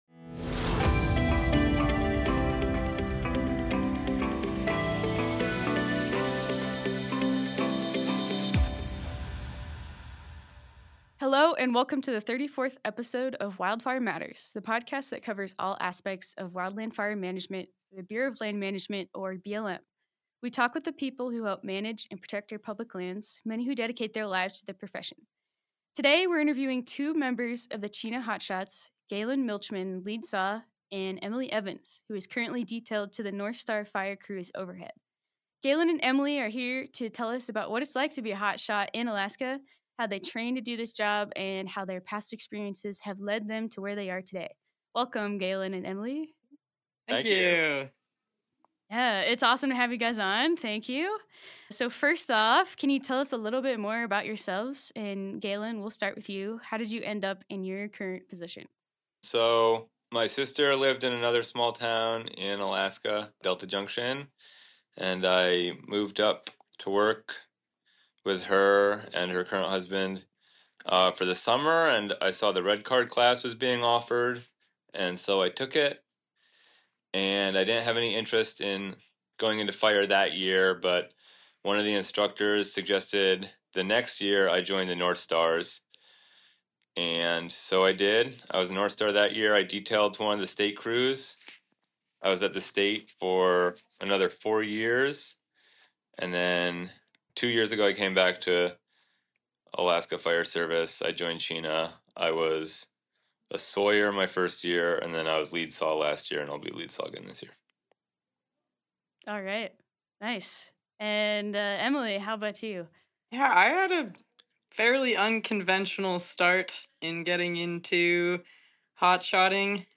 Fighting wildfire in Alaska: a chat